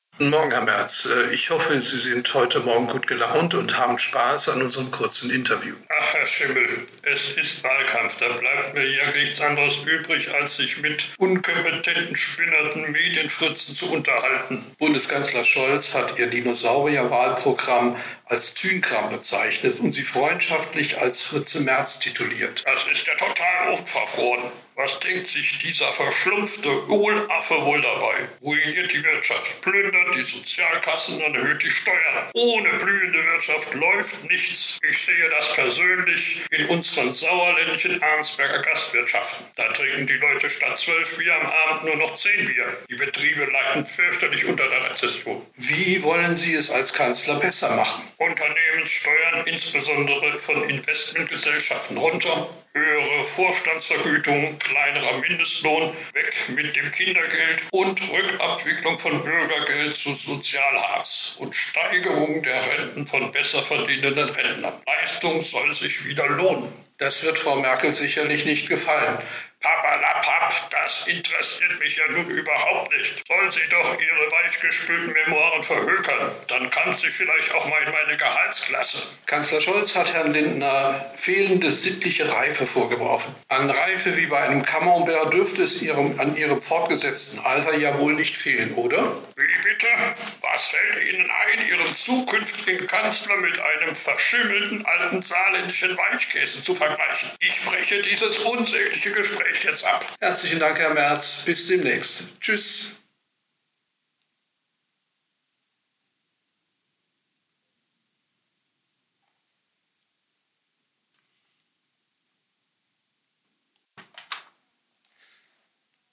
Merz regt sich fürchterlich auf über den Ausdruck Tünkram und Fritze Merz. Er erläutert sein unsoziales Uraltwahlprogramm.